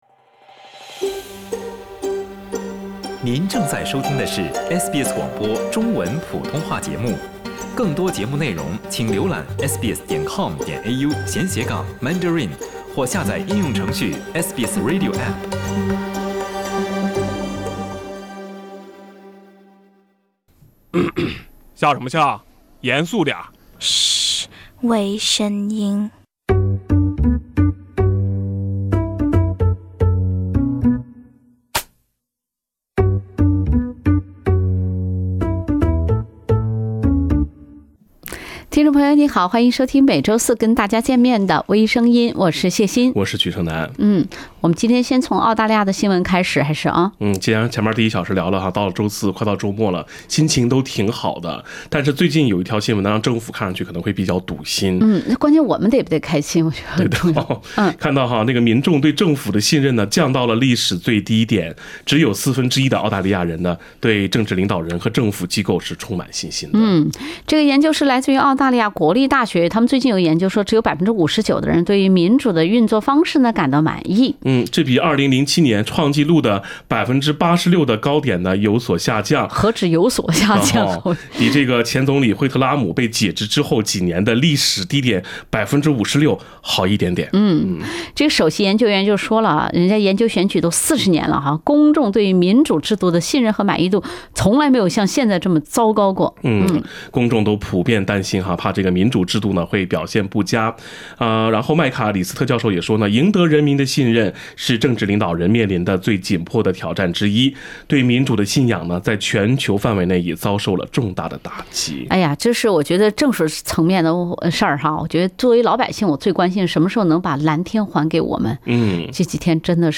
另类轻松的播报方式，深入浅出的辛辣点评，更劲爆的消息，更欢乐的笑点，敬请收听每周四上午8点30分播出的时政娱乐节目《微声音》。